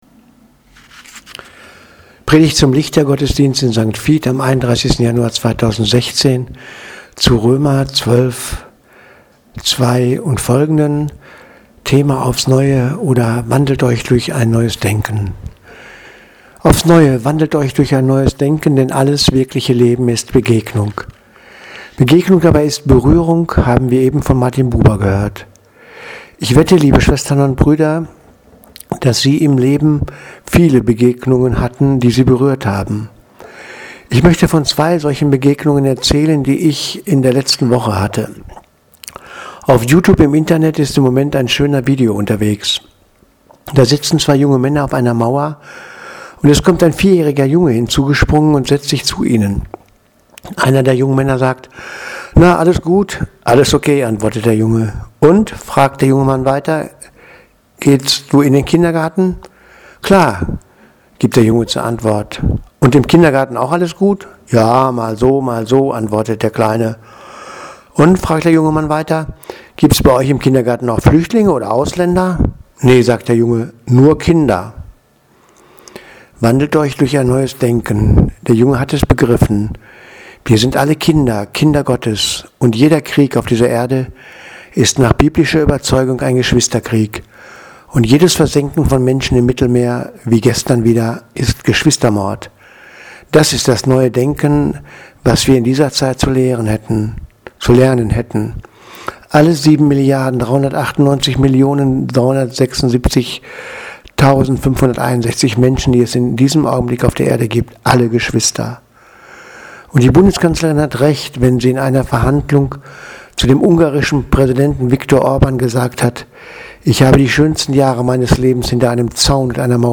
Predigt vom 31.01.2016 in St. Vit